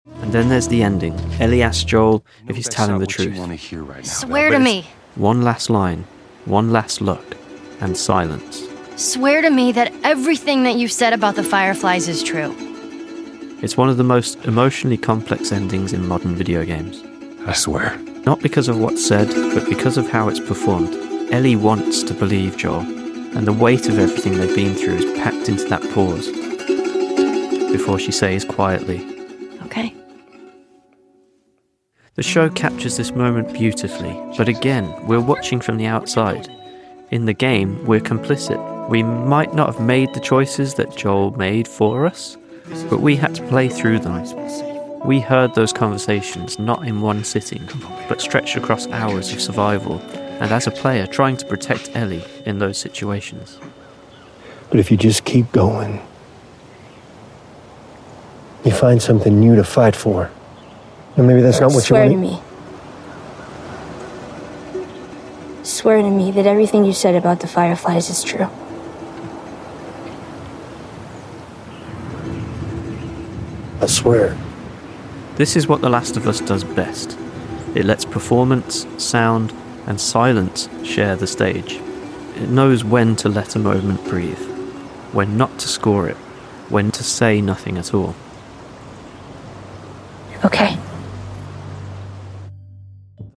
A final conversation. Ellie asks Joel if he’s telling the truth.
And silence.
And the weight of everything they’ve been through is packed into that pause before she says, quietly, ‘Okay.’